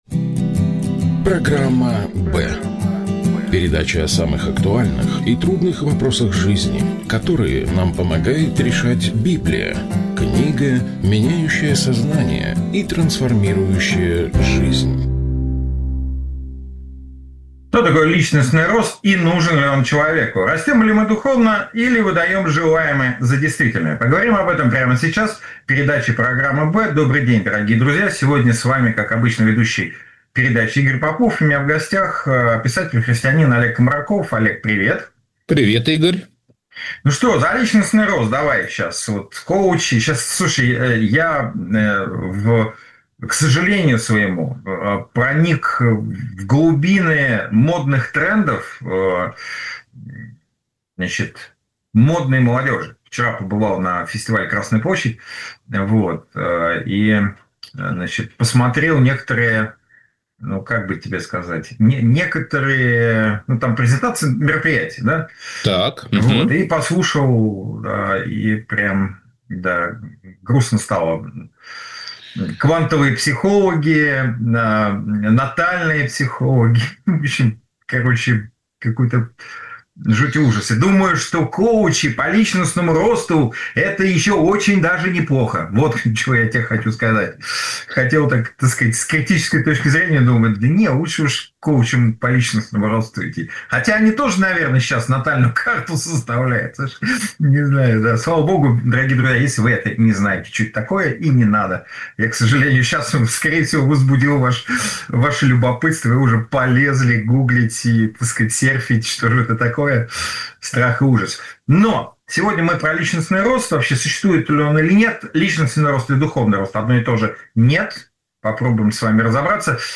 Что такое личностный рост и нужен ли он человеку? Растем ли мы духовно или выдаем желаемое за действительное? Поговорим об этом с писателем